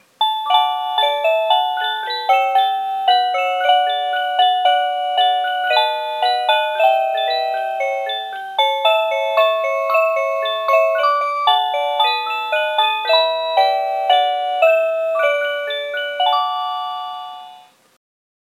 01-Cuckoo-Tune.mp3